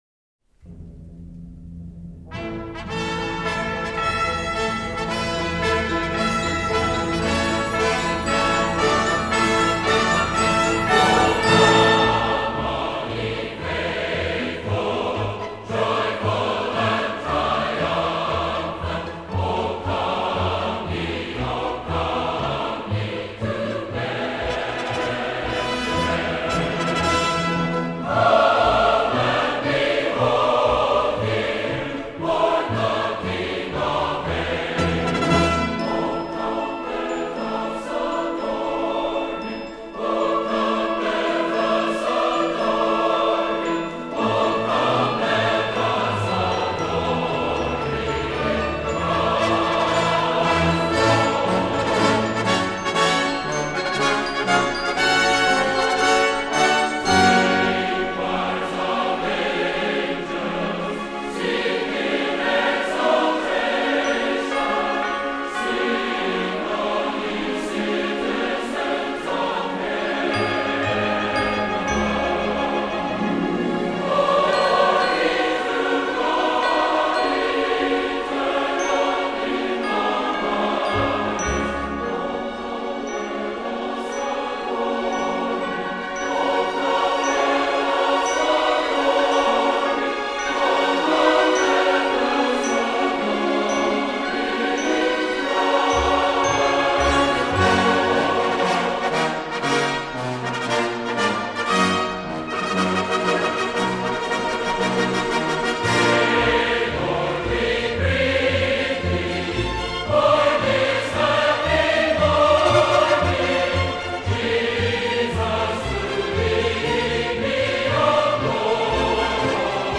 entertaining, sophisticated and merry collection.